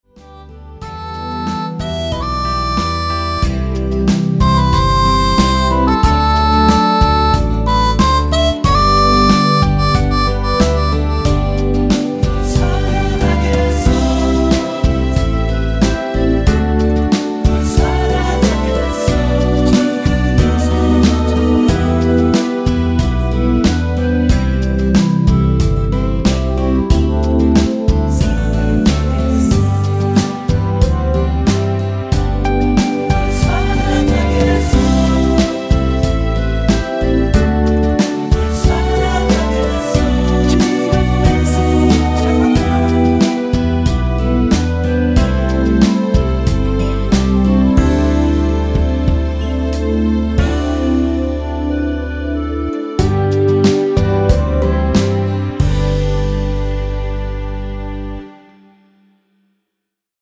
원키 코러스및 멜로디 포함된 MR 입니다.(미리듣기 참조)
앞부분30초, 뒷부분30초씩 편집해서 올려 드리고 있습니다.
중간에 음이 끈어지고 다시 나오는 이유는